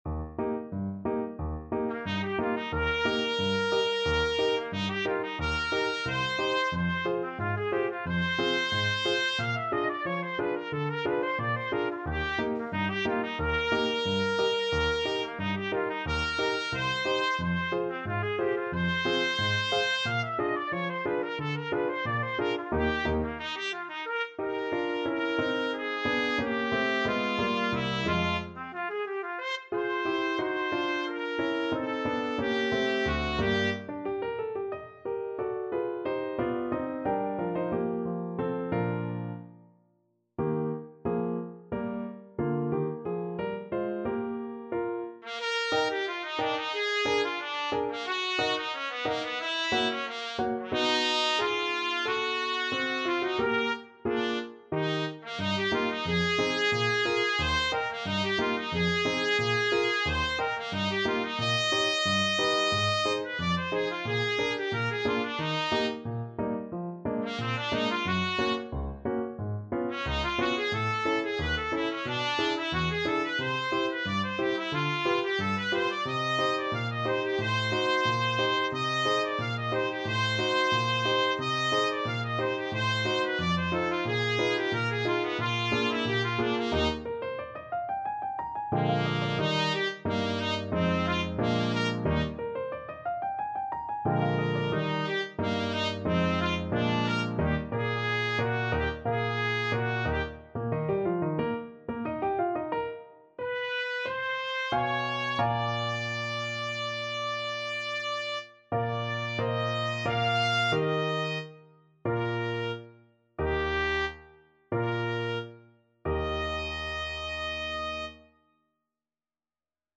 Allegro Moderato [ = c.90] (View more music marked Allegro)